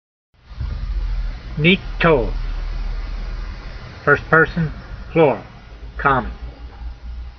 My reading (voice) in modern Israeli style is only good enough to get you started.
neek-tol